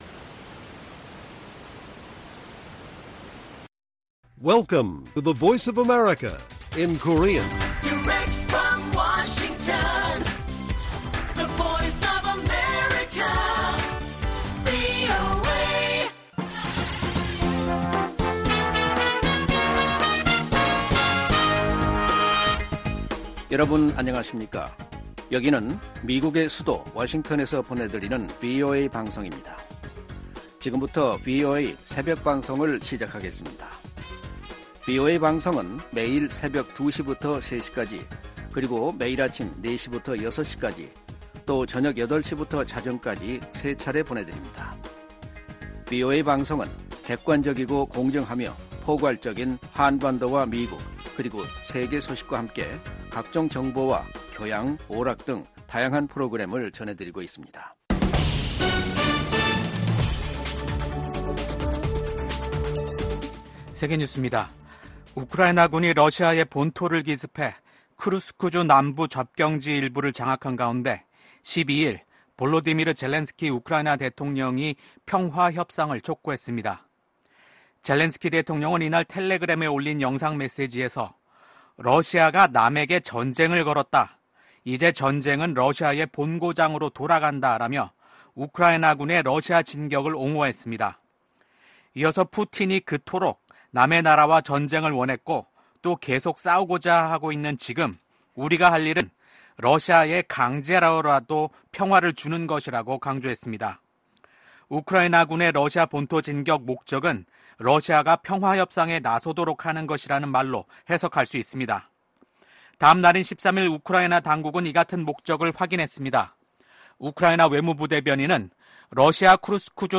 VOA 한국어 '출발 뉴스 쇼', 2024년 8월 14일 방송입니다. 북러 군사 밀착이 우크라이나뿐 아니라 인도태평양 지역 안보에도 영향을 미칠 것이라고 미국 백악관이 지적했습니다.